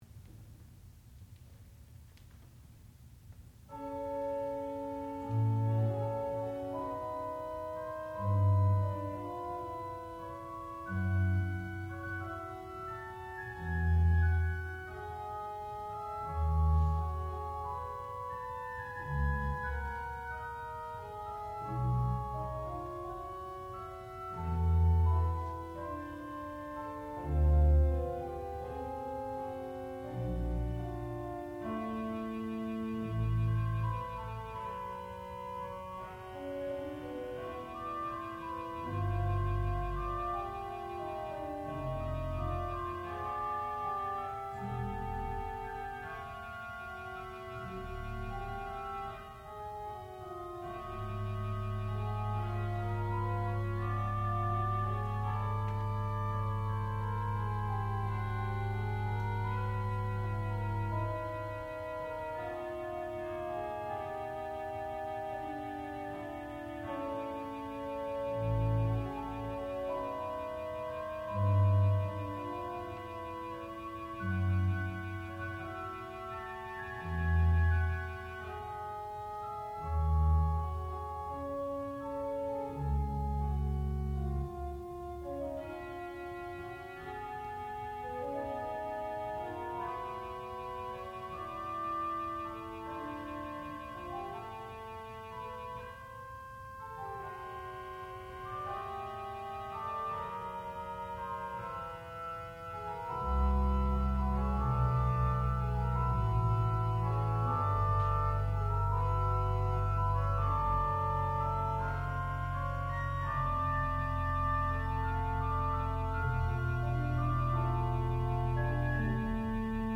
classical music
organ
Master's Recital